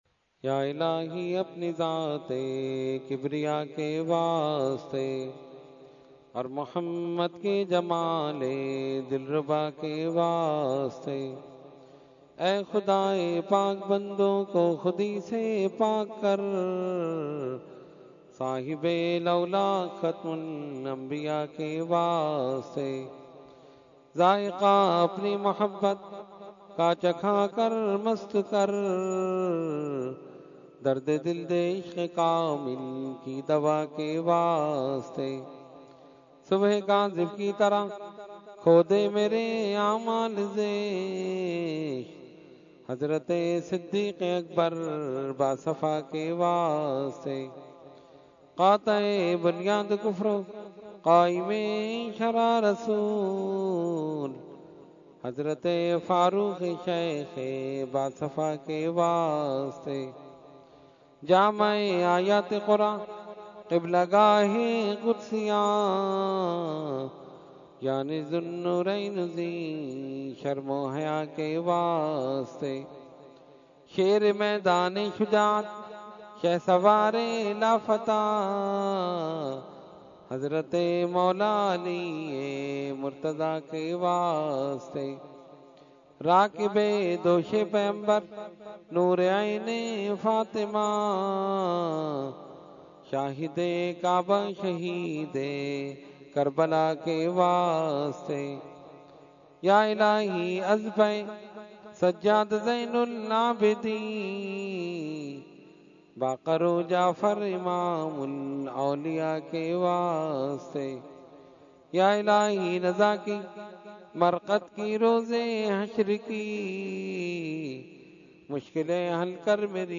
Dua – Shab e Baraat 2017 – Dargah Alia Ashrafia Karachi Pakistan